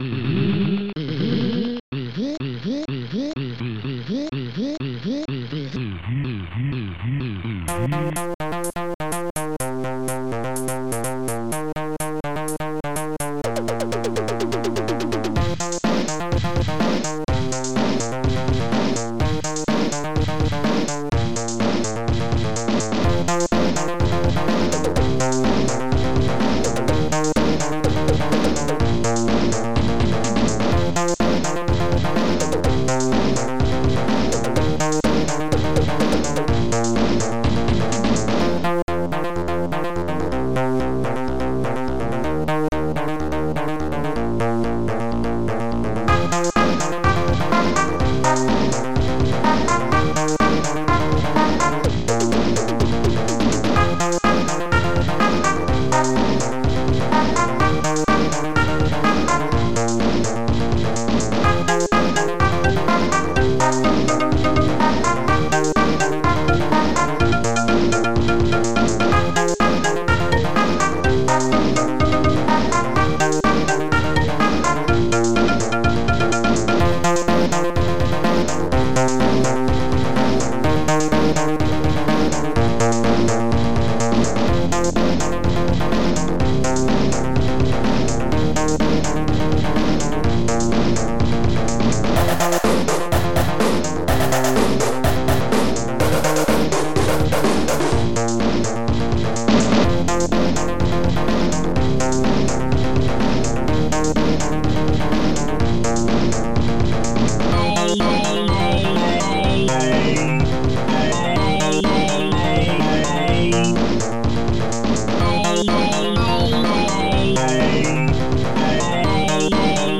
SoundTracker Module  |  1989-12-31  |  71KB  |  2 channels  |  44,100 sample rate  |  5 minutes, 7 seconds
st-02:accordion
st-02:bassdrum8
st-01:hihat2
st-02:snare10